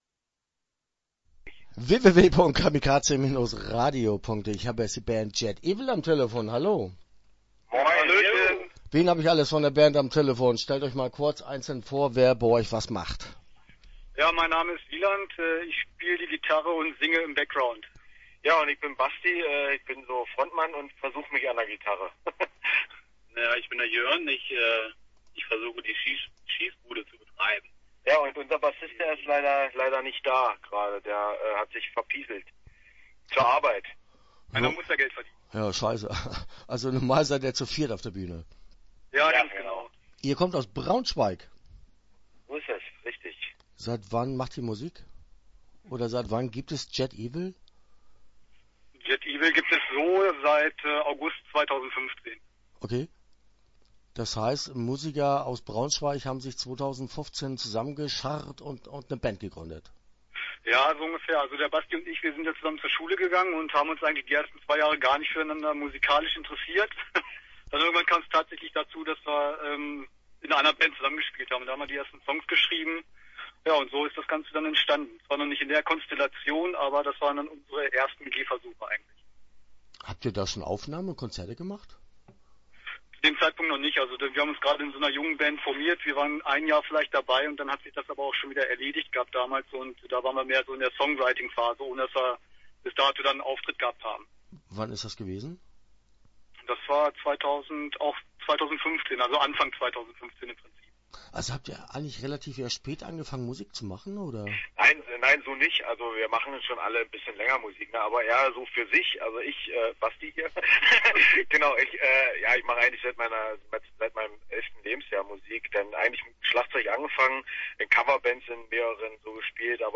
Start » Interviews » Jet Evil